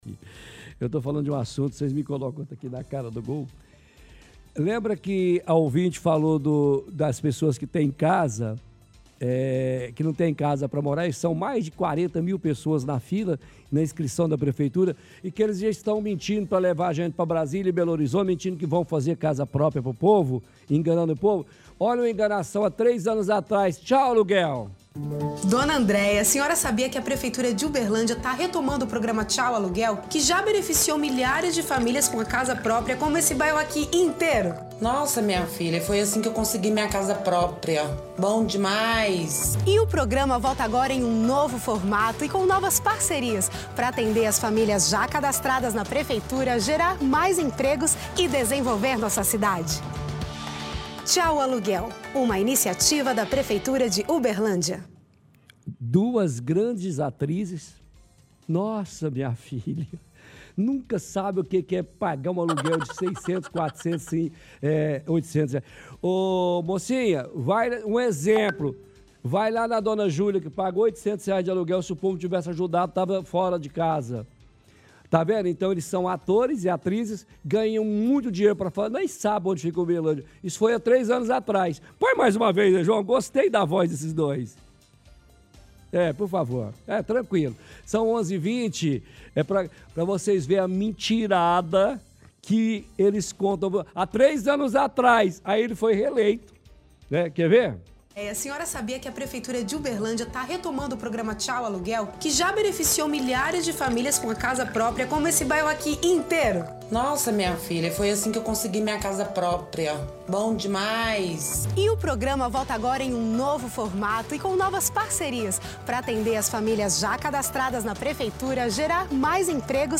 – Ouvinte reclama de falta de moradias populares e lotação de abrigos da prefeitura.